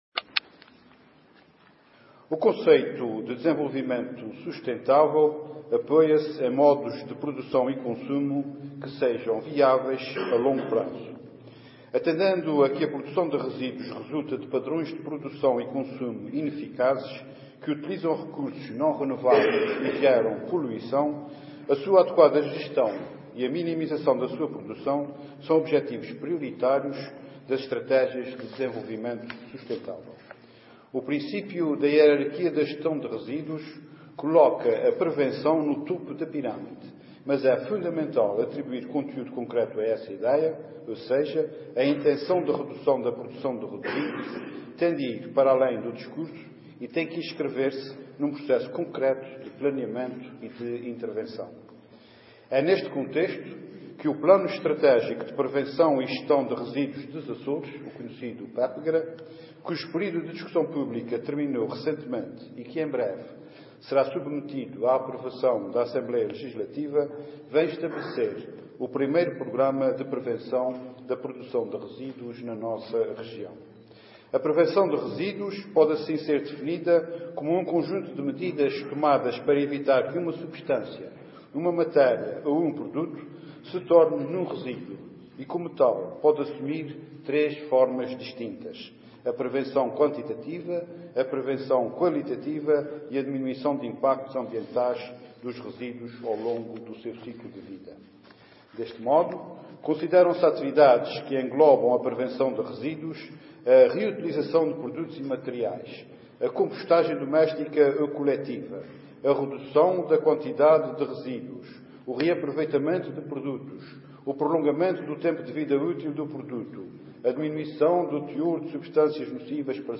Intervenção do Secretário Regional da Agricultura e Ambiente